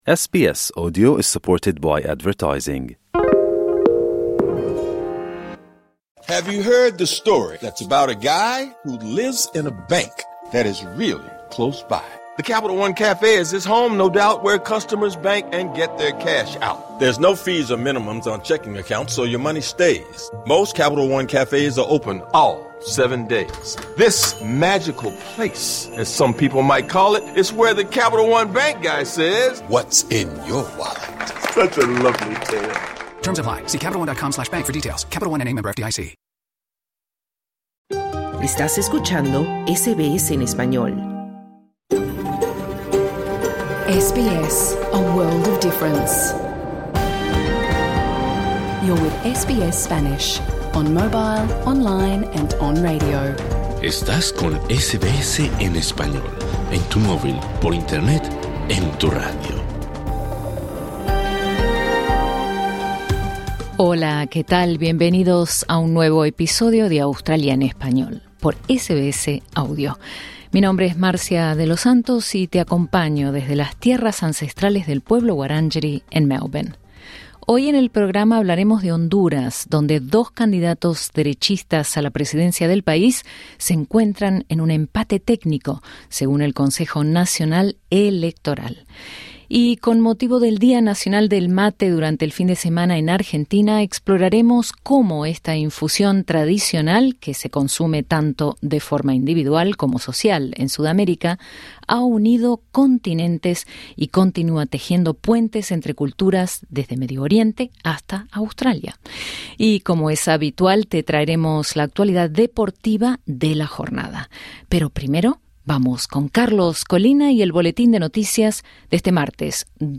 Programa en Vivo | SBS Spanish | 2 diciembre 2025 Credit: Getty Images